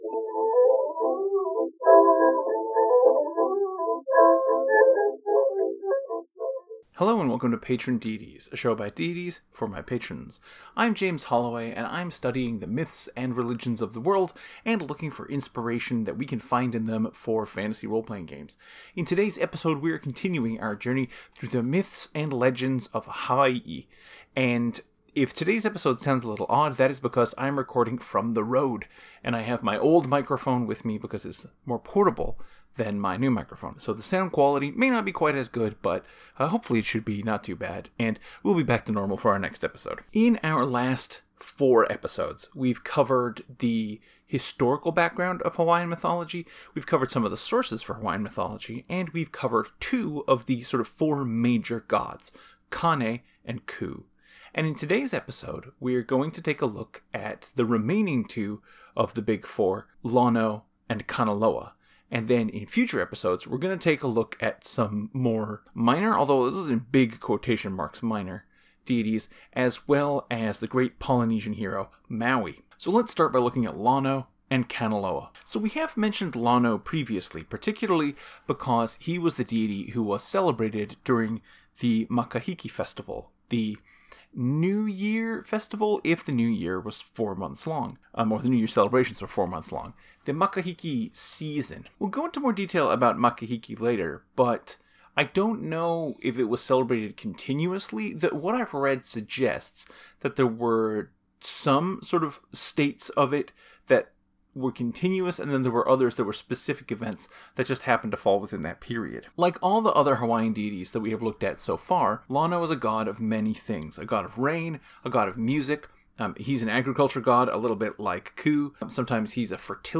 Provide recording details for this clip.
Our look at Hawaiian mythology concludes with the last two of the four major gods. Please excuse the fuzzy sound of this episde!